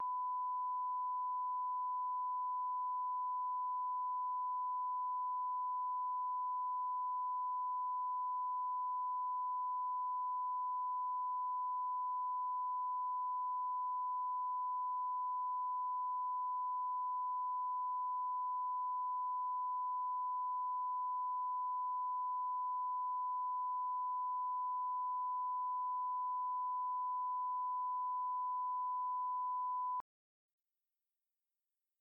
-30dB 1kHz Both Channels